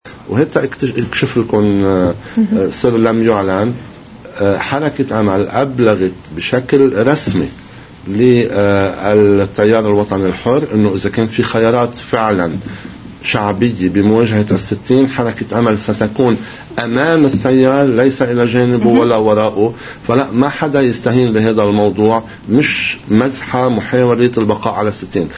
كشف مستشار رئاسة الجمهورية جان عزيز سر لم يعلن ضمن برنامج “اجندة اليوم” يتعلق حول حركة امل، وفيه: